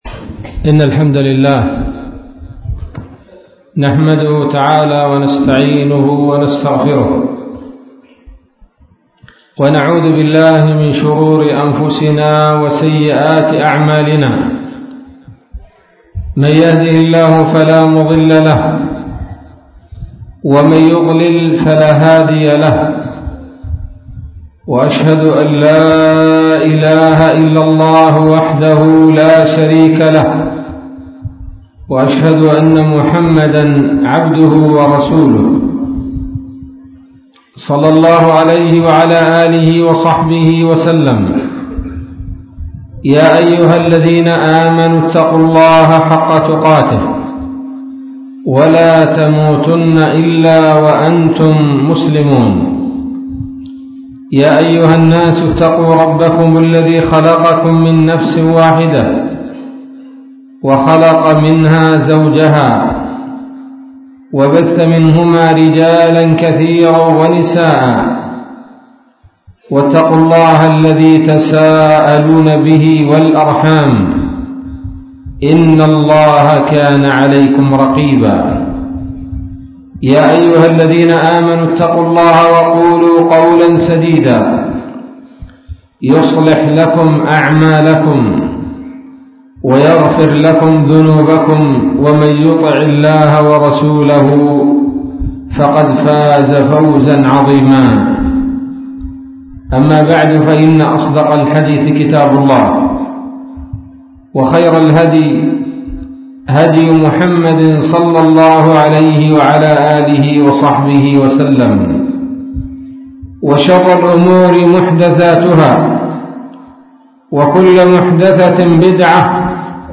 ليلة السبت 20 ربيع أول 1439هـ، منطقة خور عميرة